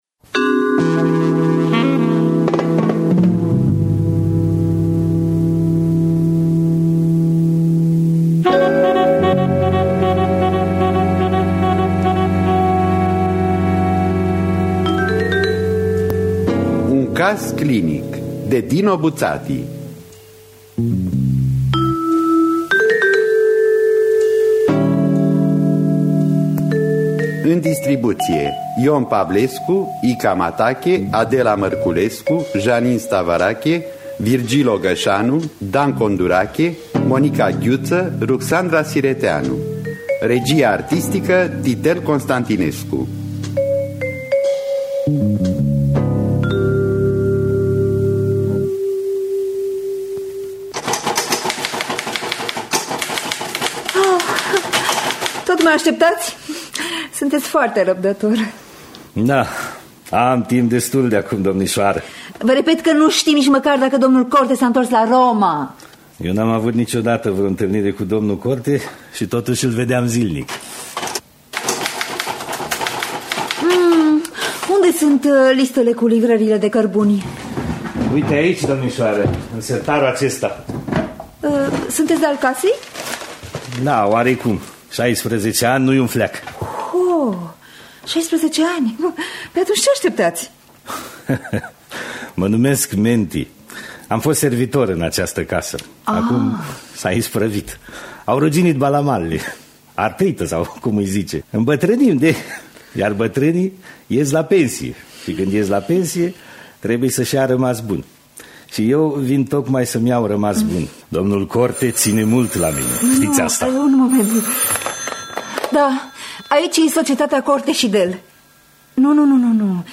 Dramatizare